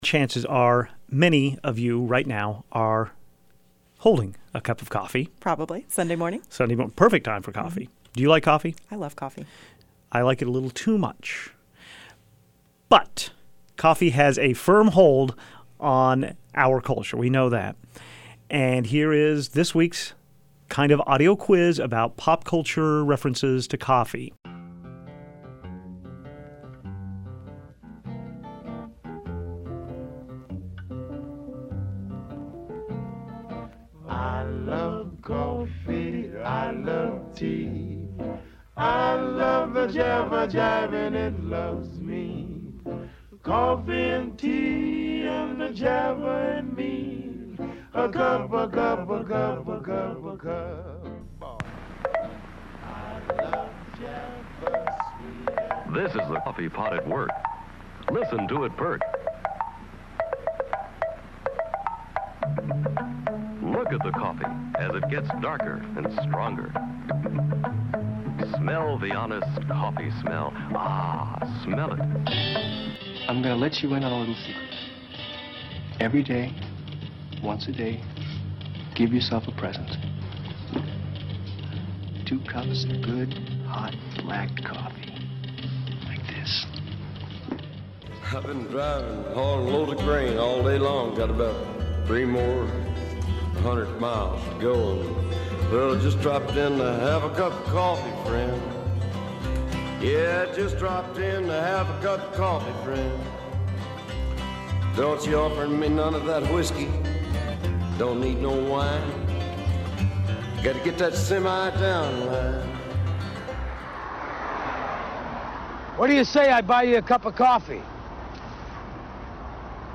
In this weekend montage, we salute the coffee drinker with ten pop culture references about the drink.